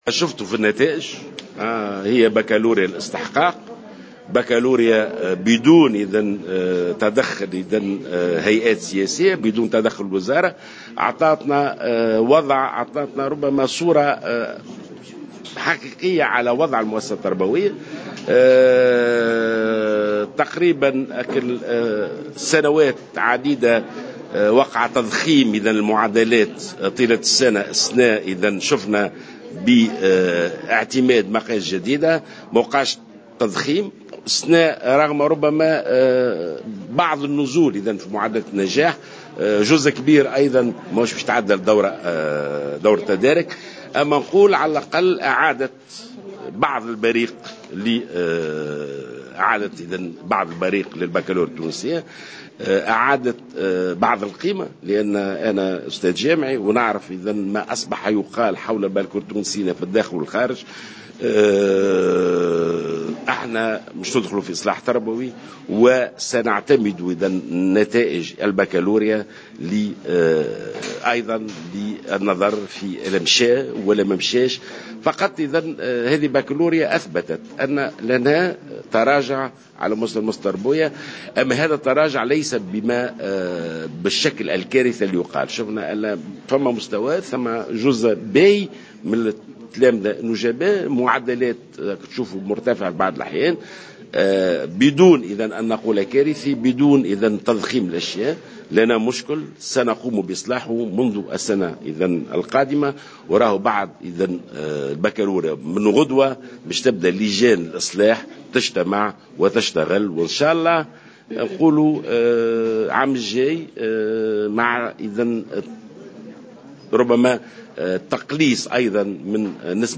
وأضاف جلول على هامش ندوة صحفية عقدتها الوزارة للإعلان عن نتائج امتحان البكالوريا أنه تم تسجيل تراجع من حيث نسب النجاح في امتحان البكالوريا مقارنة بالسنوات الفارطة،لكنه تراجع غير كارثي،وفق تعبيره